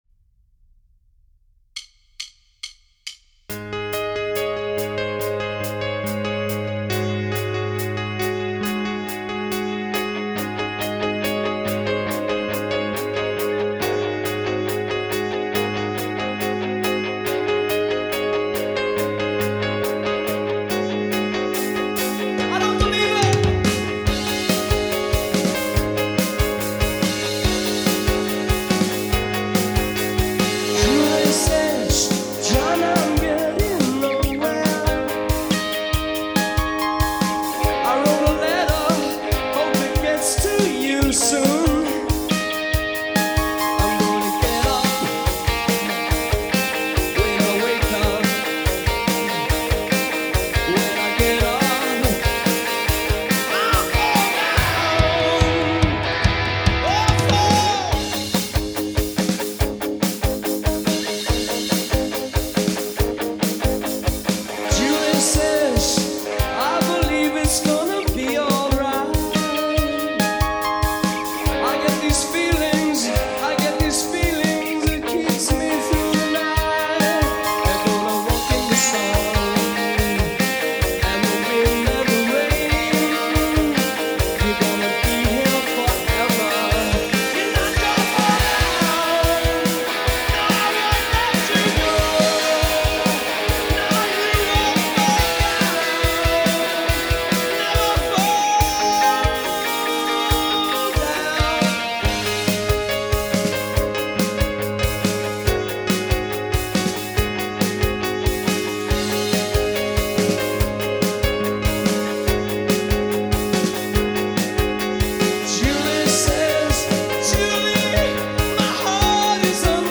BPM : 141
Tuning : Eb
With vocals
Based on the 1982 Hammersmith Palais live version